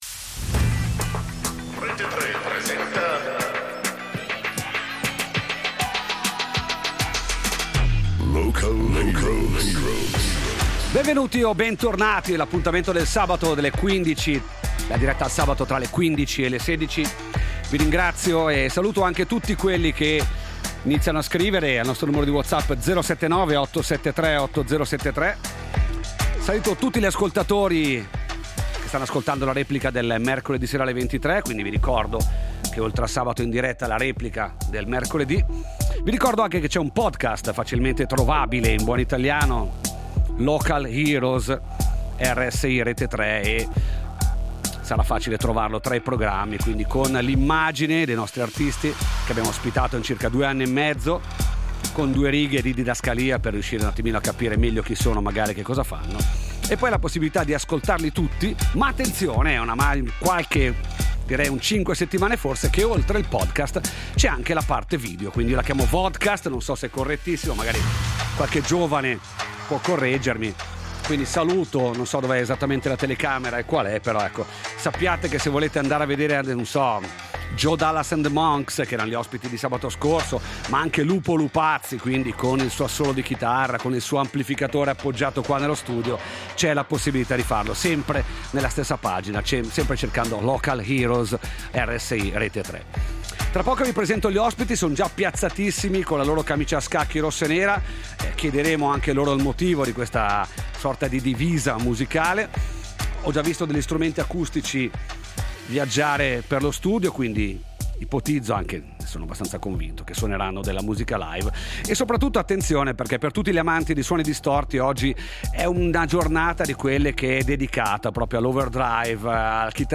Gli Örök, rock/punk band nata nel 2019 tra Maccagno e i Grigioni
Local Heroes Gli Örök Gli Örök, rock/punk band nata nel 2019 tra Maccagno e i Grigioni 11.04.2026 57 min RSI - Rete Tre Contenuto audio Disponibile su Scarica Energia da punk californiano, ma con quel twist da cantautorato italiano che ti fa sorridere. In studio
pronti anche a passare in acustico con basso e chitarra.